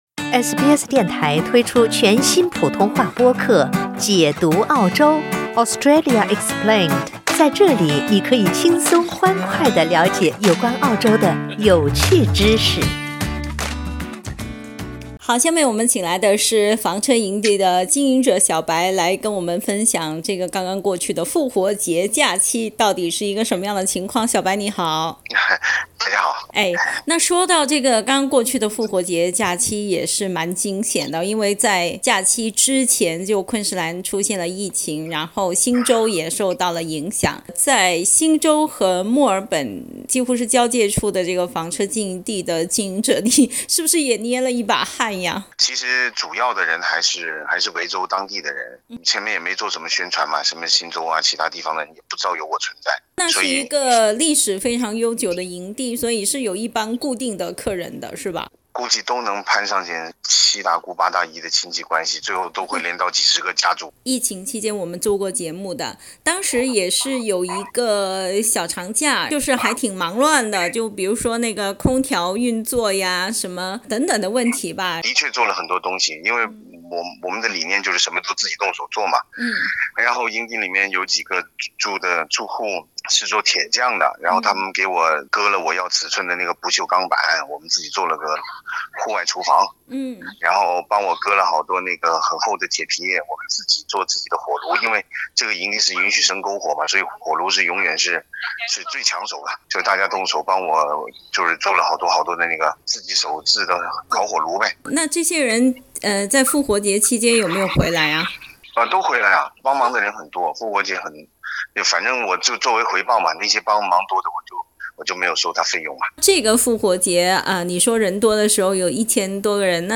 （请听采访） 澳大利亚人必须与他人保持至少1.5米的社交距离，请查看您所在州或领地的最新社交限制措施。